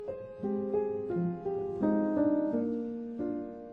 PLAY sad piano music
sad-piano.mp3